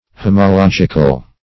homological - definition of homological - synonyms, pronunciation, spelling from Free Dictionary
Homological \Ho`mo*log"ic*al\, a.